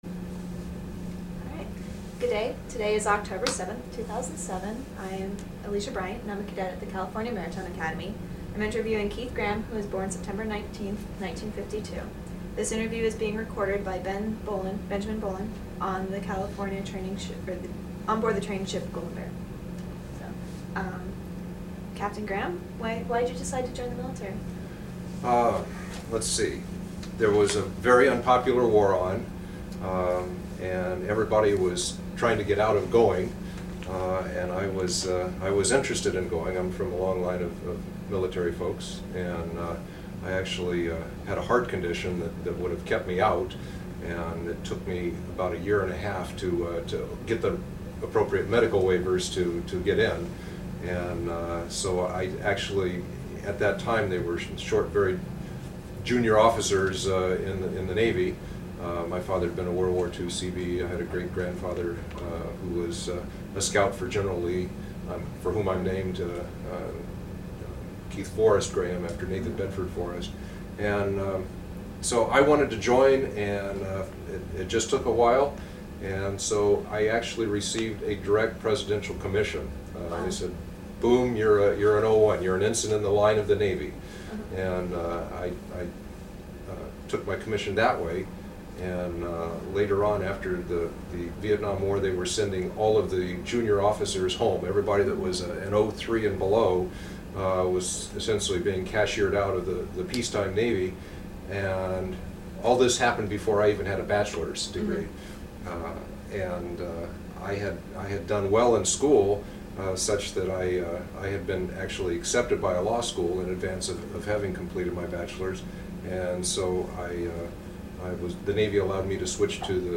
Interview conducted